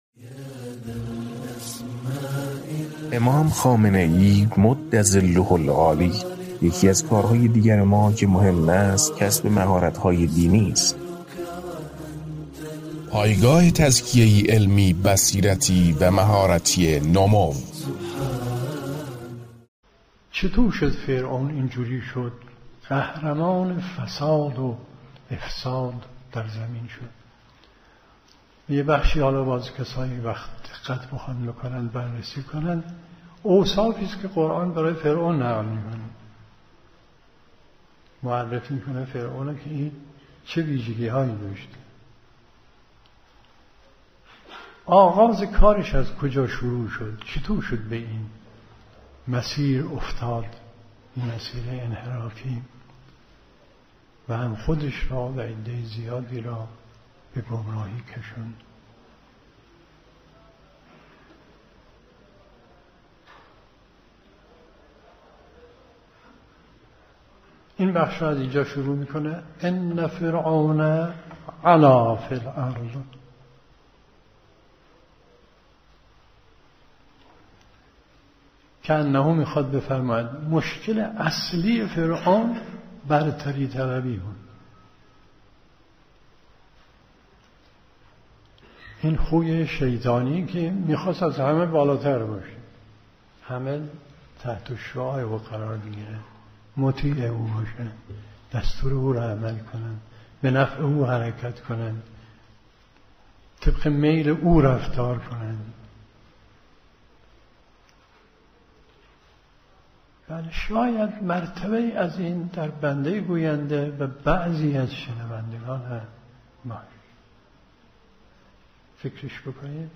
در این فایل صوتی آیت الله مصباح یزدی بر اساس آیه ای از قرآن کریم رذایل اخلاقی فرعون را شرح می دهند